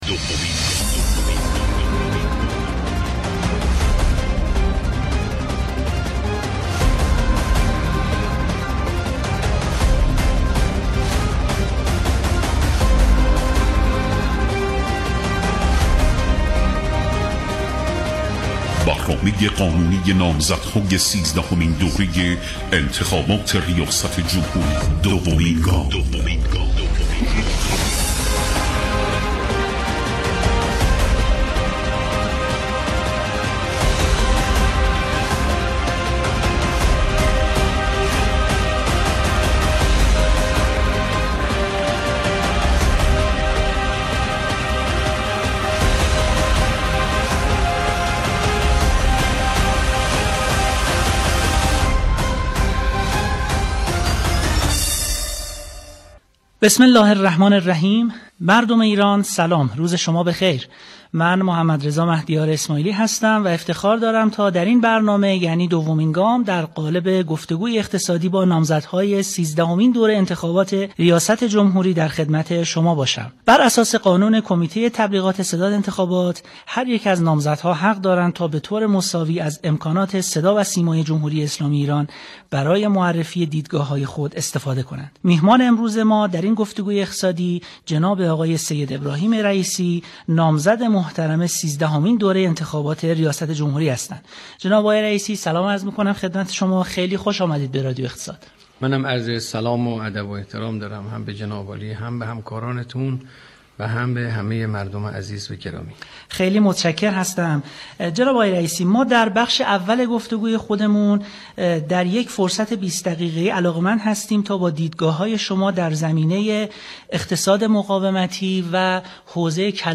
صوت/ گفتگوی ویژه رئیسی با رادیو اقتصاد
فایل صوتی گفتگوی حجت الاسلام رئیسی نامزد ریاست جمهوری با رادیو اقتصاد.